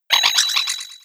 876_female.wav